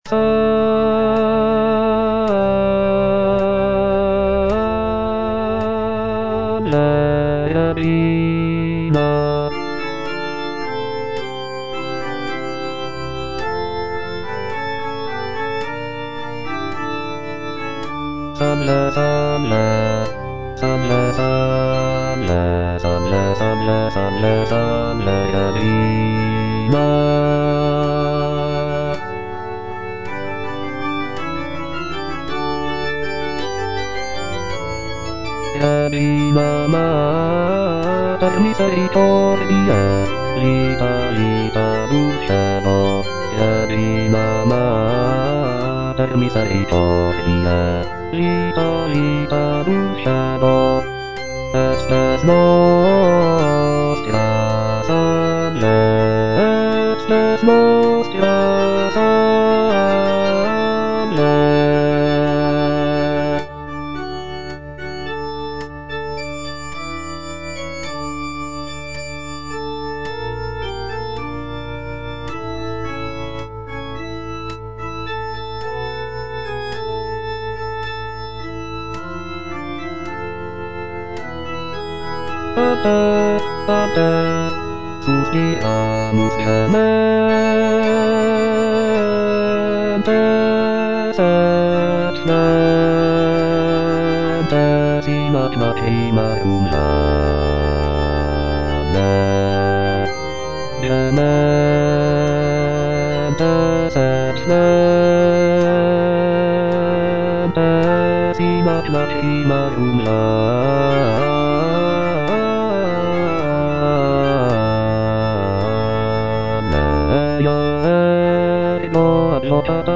M.A. GRANCINI - SALVE REGINA Bass (Voice with metronome) Ads stop: auto-stop
Grancini's musical rendition of this text features rich harmonies, expressive melodies, and contrapuntal writing.